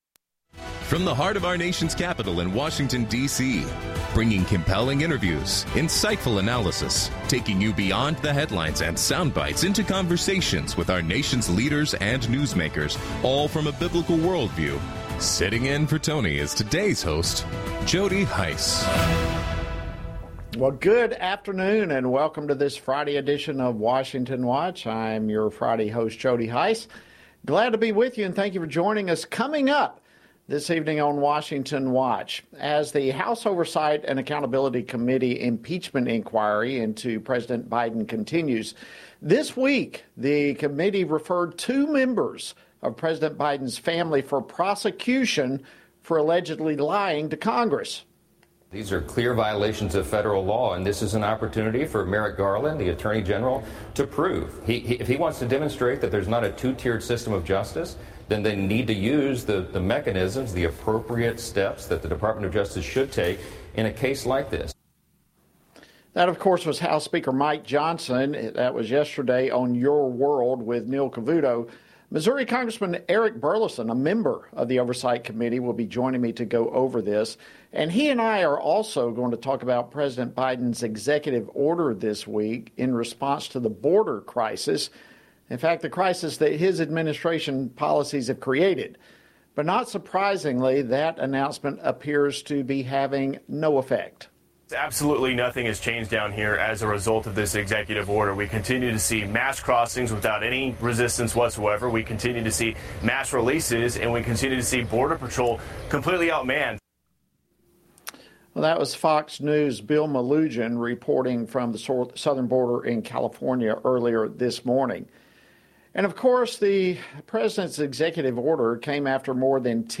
On today’s program, hosted by Jody Hice: Eric Burlison, U.S. Representative for the 7th District of Missouri, discusses the referral by the House Oversight and Accountability Committee for the Department of Justice to charge Hunter and James Biden with lying to Congress. Ron Estes, U.S. Representative for the 4th District of Kansas, provides analysis of the May jobs report and responds to the effort by the Left to put a positive spin on illegal immigration.